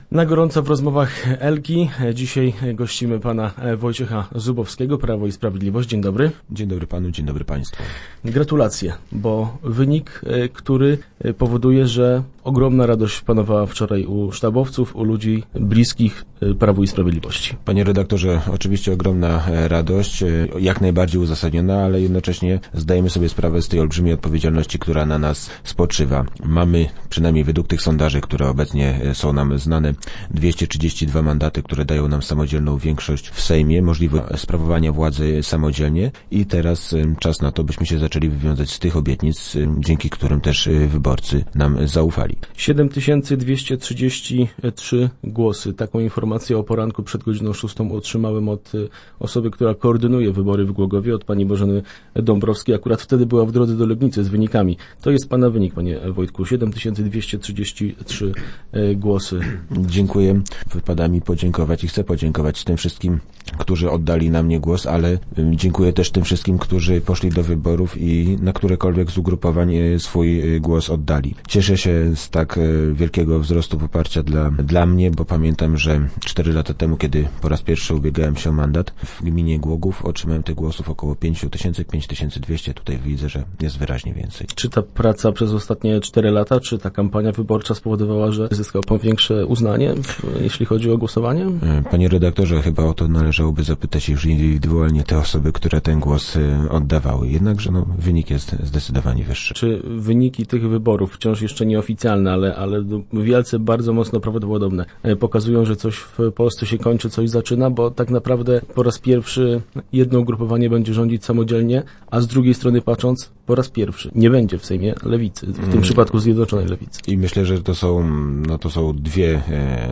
Wojciech Zubowski, ubiegający się o kolejną kadencję poselską, był gościem poniedziałkowych Rozmów Elki. - Wynik cieszy, ale i zobowiązuje do dalszego działania – mówił w radiowym studiu.